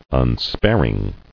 [un·spar·ing]